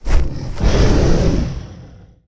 CosmicRageSounds / ogg / general / combat / creatures / dragon / he / attack2.ogg